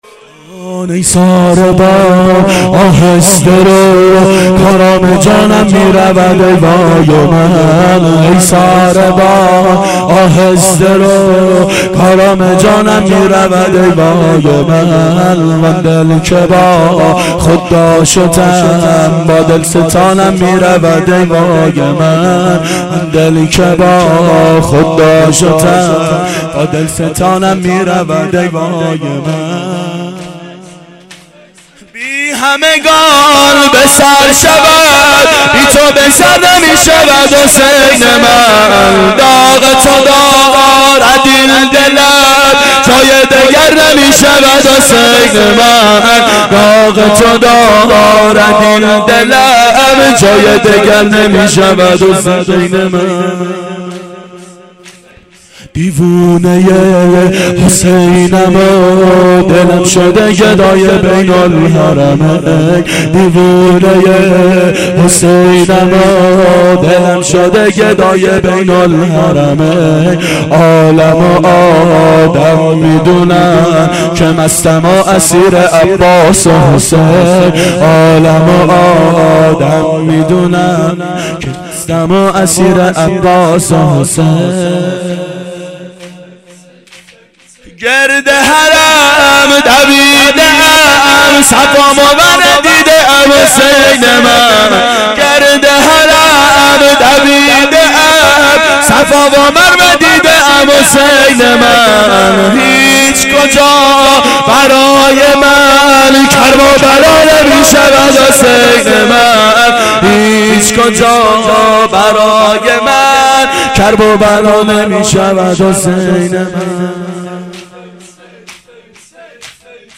شب عاشورا 1390 هیئت عاشقان اباالفضل علیه السلام